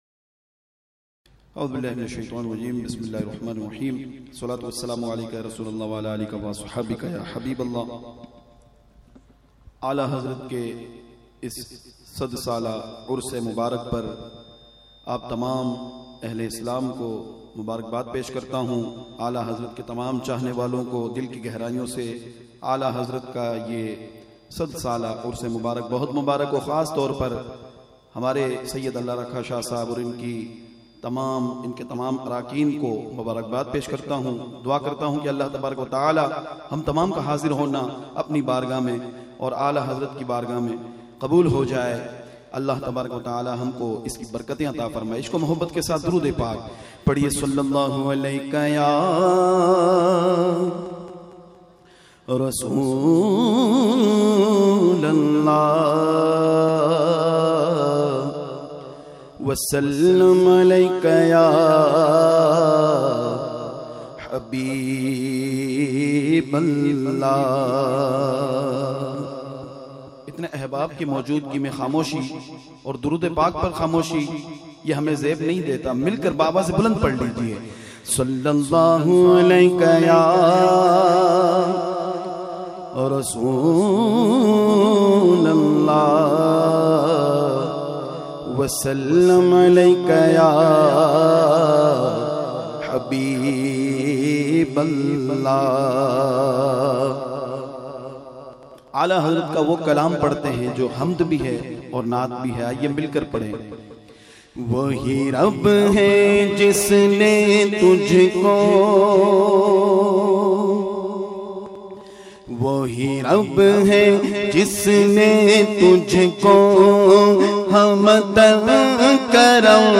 موضوع حمد